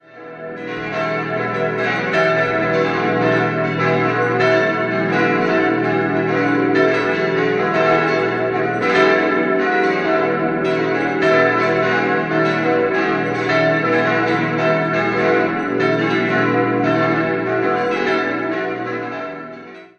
6-stimmiges erweitertes Westminster-Geläute: h°-e'-fis'-gis'-h'-cis'' Die fis¹-Glocke wurde im Jahr 1543 von Gregor Löffler gegossen.